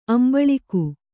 ♪ ambaḷaku